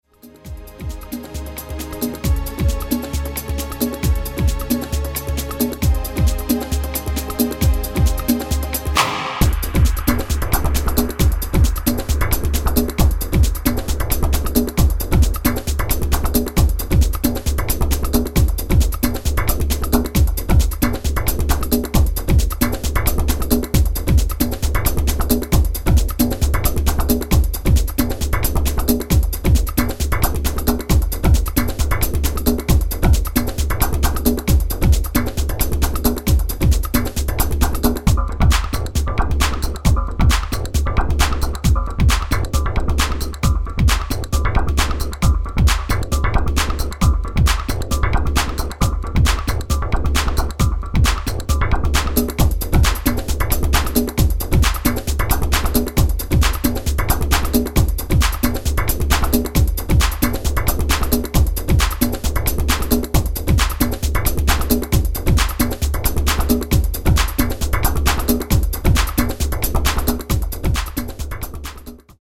basic house tracks